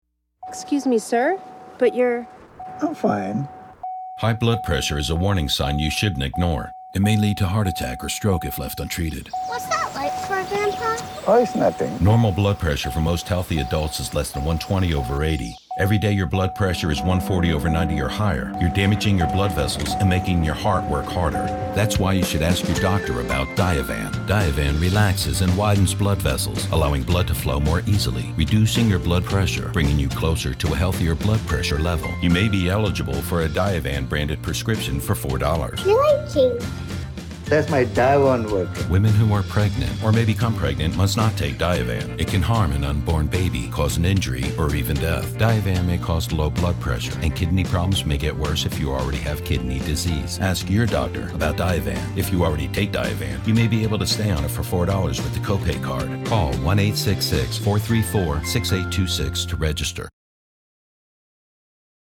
Deep english speaking voice, authorative, manly, believable, honest, raspy, rugged.
Sprechprobe: Sonstiges (Muttersprache):